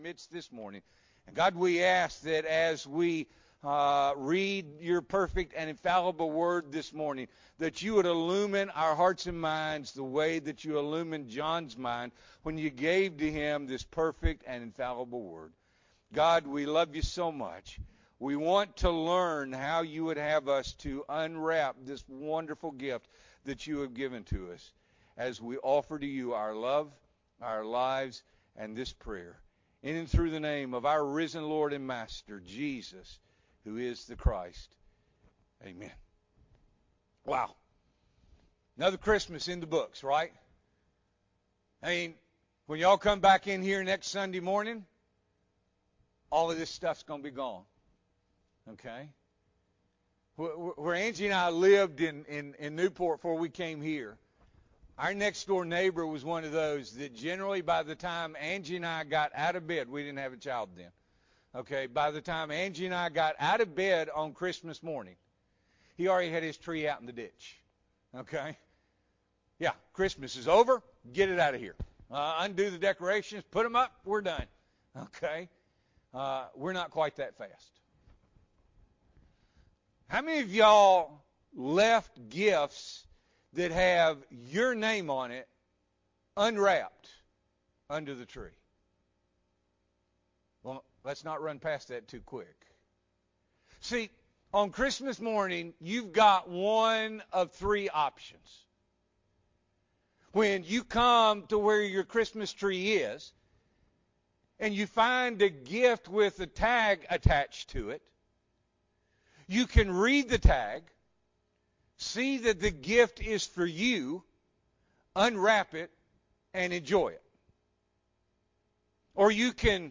December 26, 2021 – Morning Worship